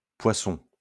Poisson [pwa.sɔ̃